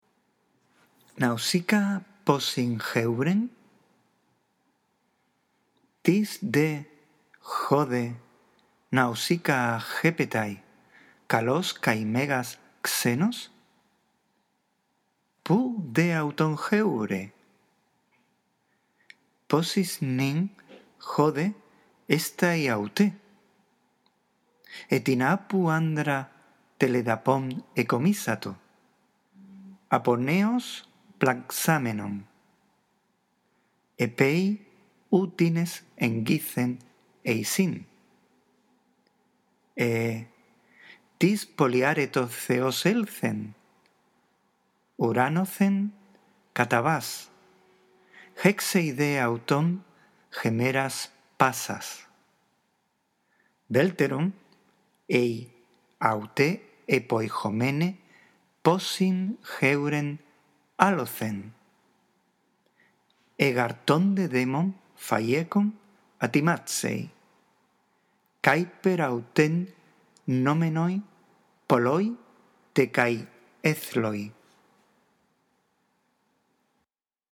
La audición de esta grabación puede contribuir a mejorar tu lectura del griego